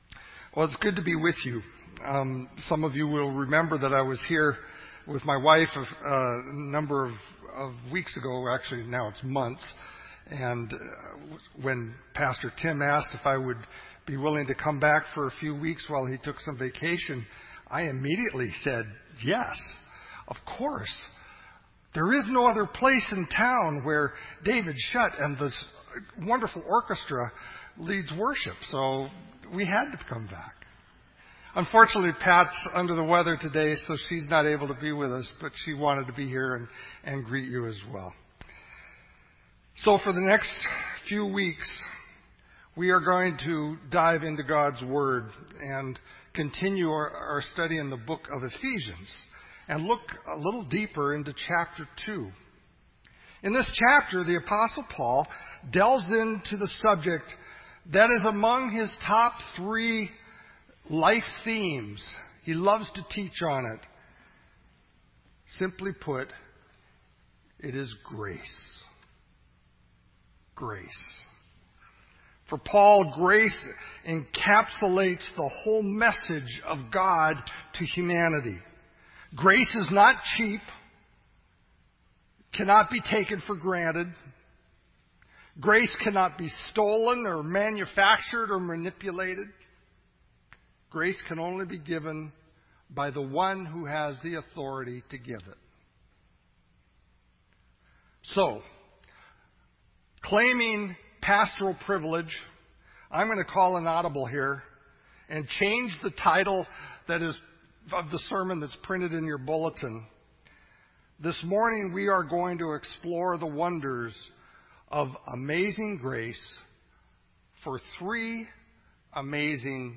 This entry was posted in Sermon Audio on June 27